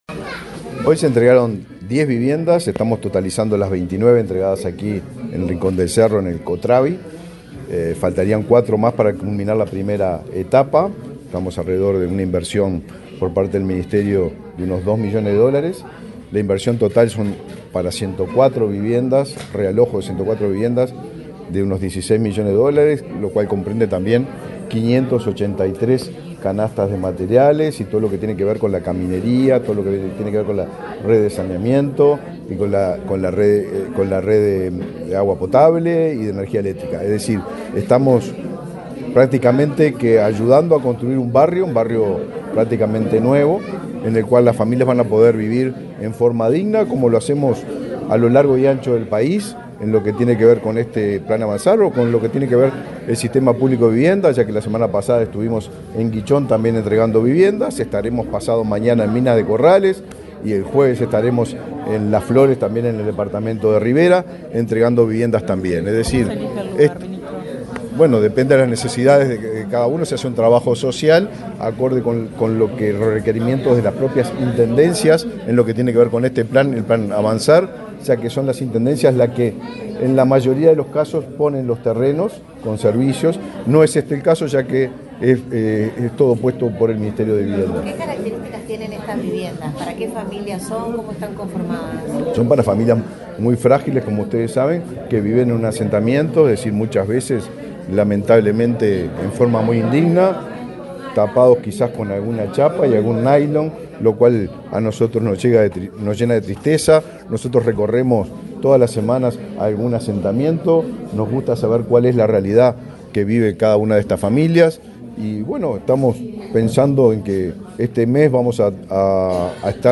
Declaraciones del ministro de Vivienda, Raúl Lozano
Luego, el secretario de Estado dialogó con la prensa.